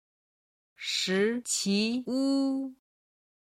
今日の振り返り中国語音源
「食其屋(shí qí wū)すき家」
01-shiqiwu.mp3